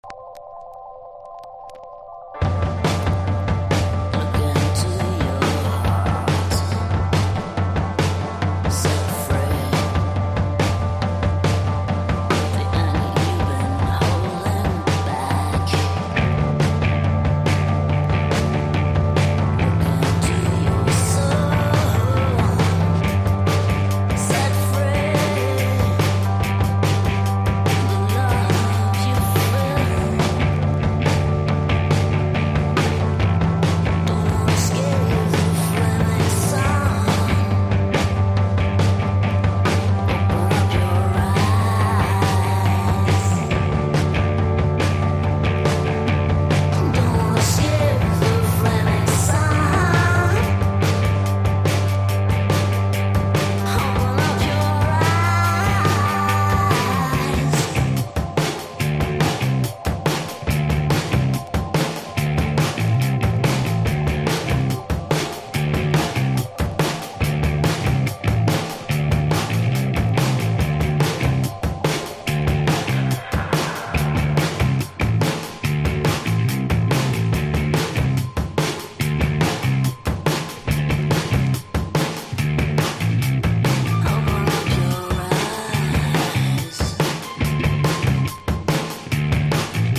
1. 00S ROCK >
INDIE DANCE